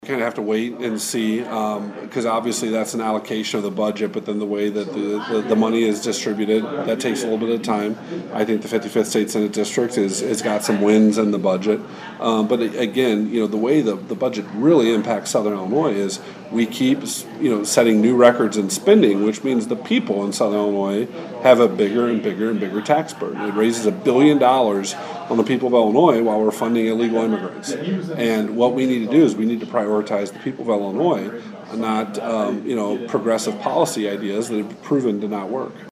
State Senator Jason Plummer was in Vandalia on Wednesday to address the monthly meeting of the Greater Fayette County Chamber of Commerce.